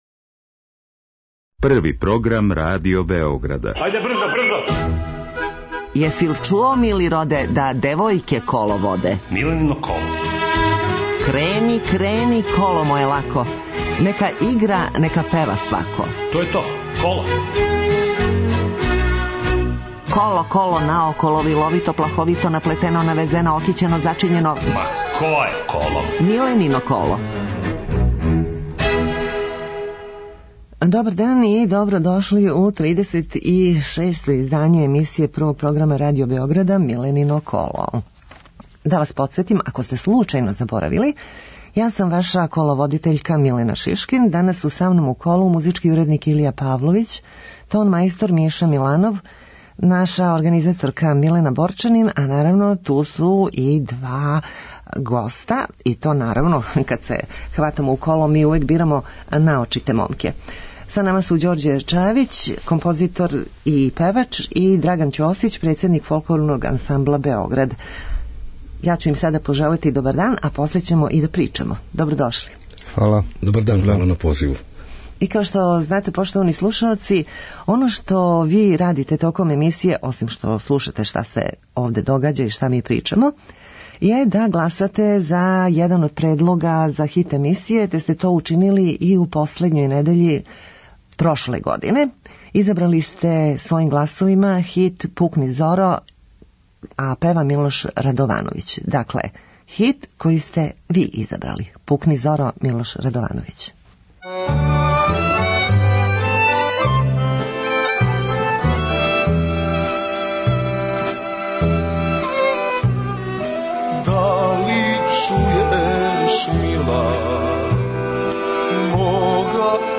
Емисија се емитује недељом од 11.05 до 12.00 о народној музици, искључиво са гостима који су на било који начин везани за народну музику, било изворну, било новокомпоновану (певачи, композитори, текстописци, музичари...). Разговор са гостом забавног карактера - анегдоте из професионалног живота, најдража песма, највећи успех, хоби и томе слично.